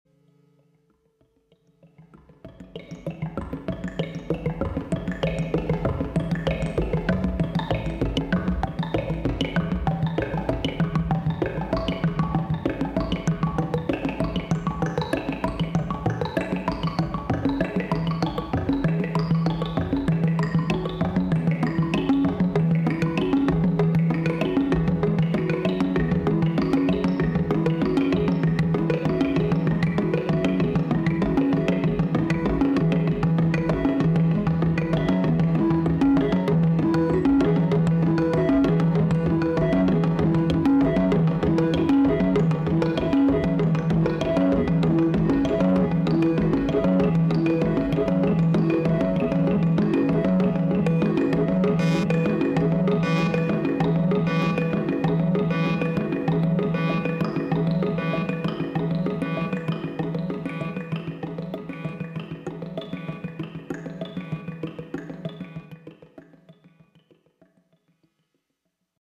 Loving this stereo LPG sound effects free download
Loving this stereo LPG it’s made some complicated tasks so much easier, and it sounds great!